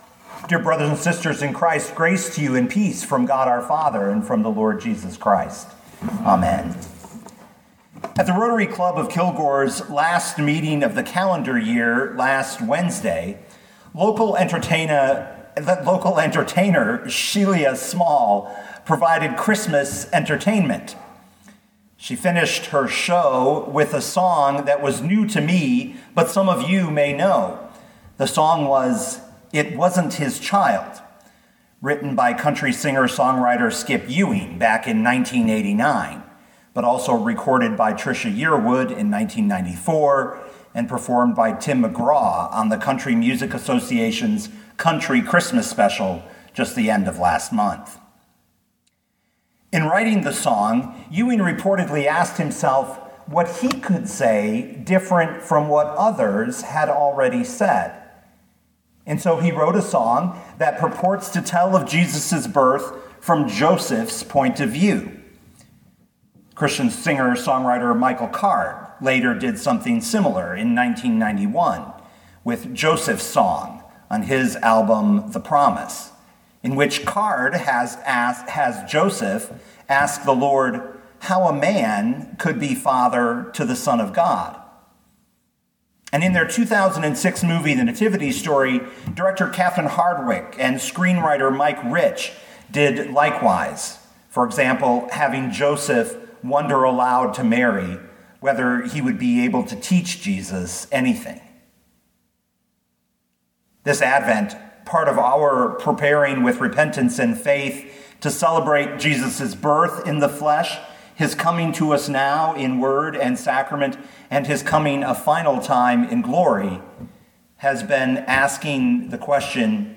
John 8:31-59 Listen to the sermon with the player below, or, download the audio.